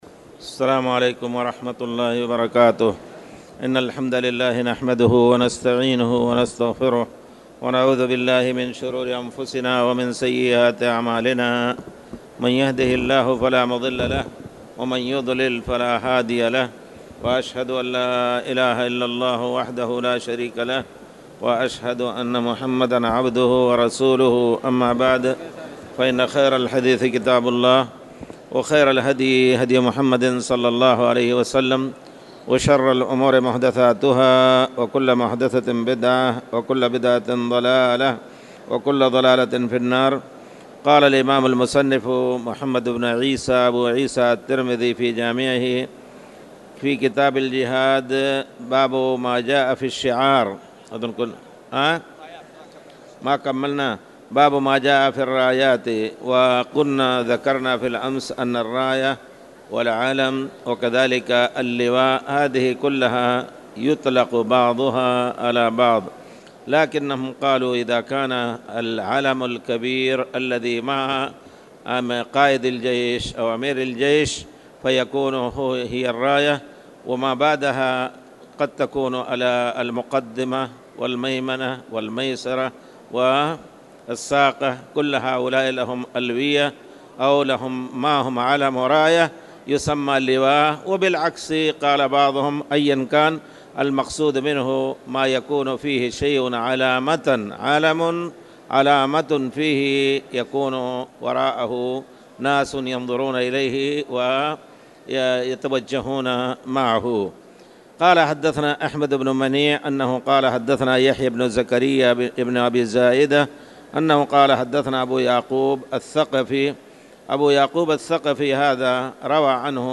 تاريخ النشر ٨ شعبان ١٤٣٨ هـ المكان: المسجد الحرام الشيخ